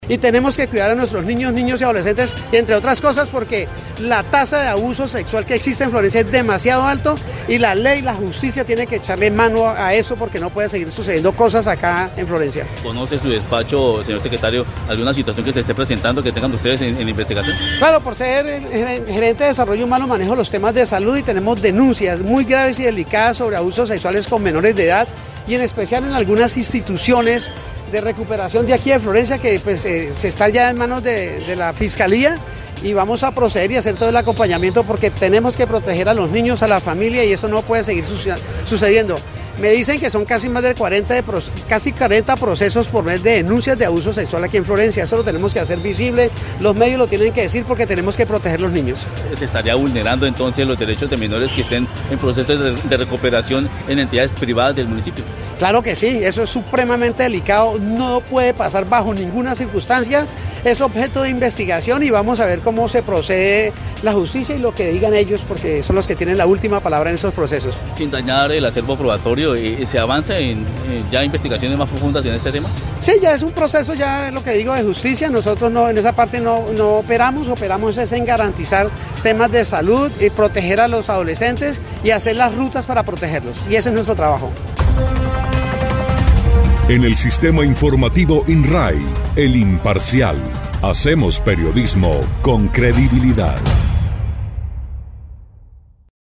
Miguel Ángel Galeano Mahecha, secretario para la gerencia del desarrollo humano en el municipio de Florencia, explicó que el tema preocupa como quiera que son varias las denuncias, incluso superan las 40, por delitos que afectan los derechos sexuales de menores de edad.